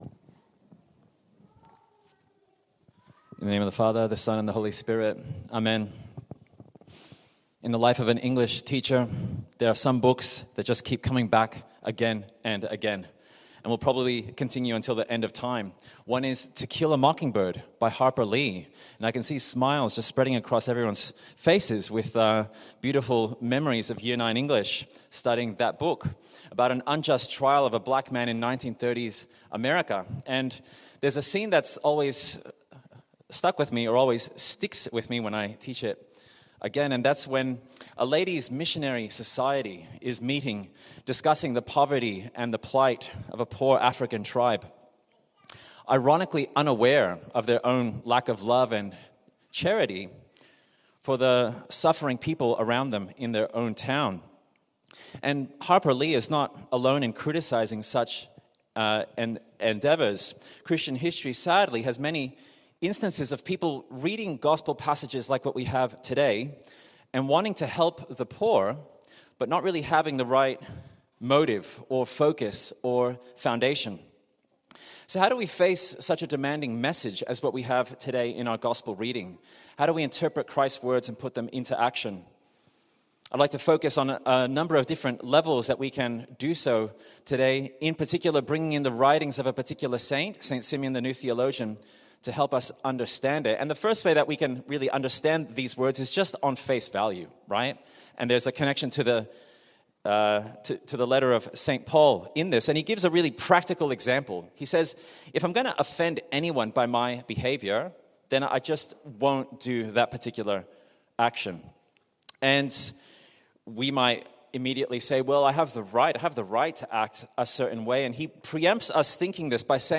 The Good Shepherd Orthodox Church: The Good Shepherd Orthodox Church: Sermons